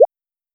recharge_capsule_5.wav